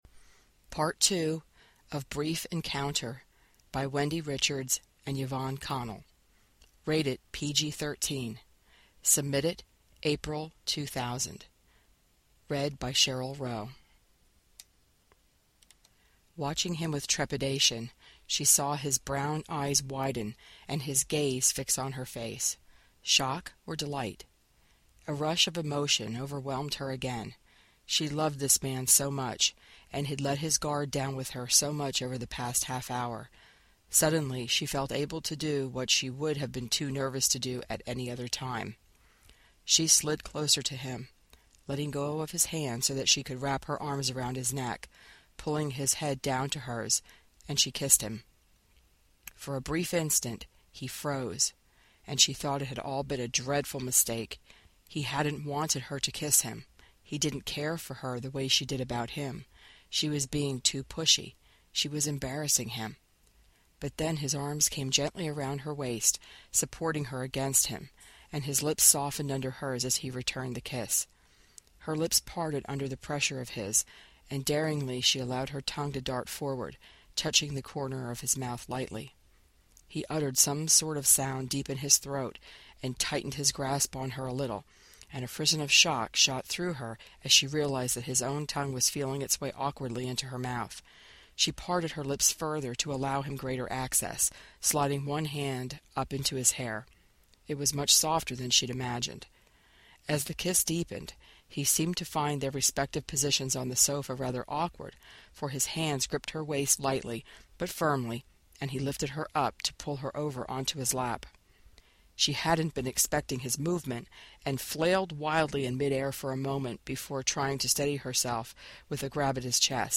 AudioFic